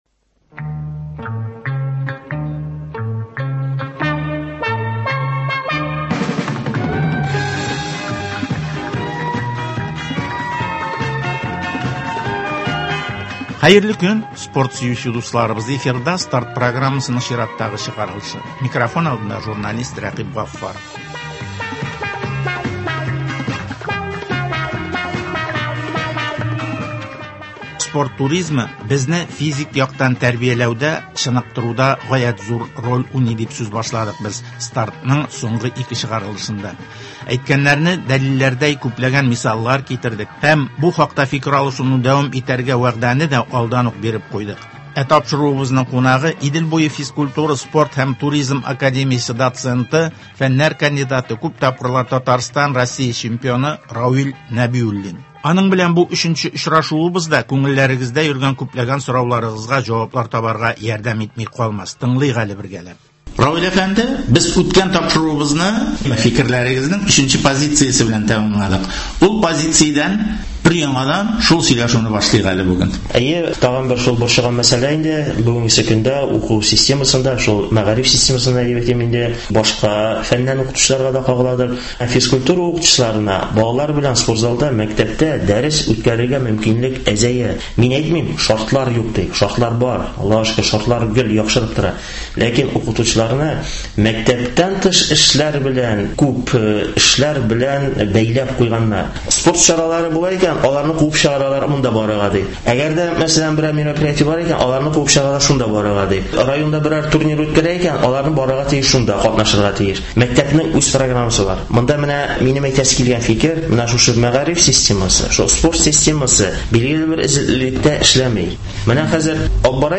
Казан – Россиянең спорт башкаласы, авылда спортның үсеше, дөнья күләмендәге чемпионатларга әзерләнү, районнар масштабындагы ярышларны үткәрү – әлеге һәм башка темалар хакында спортчылар, җәмәгать эшлеклеләре һәм спорт өлкәсендәге белгечләр белән әңгәмәләр.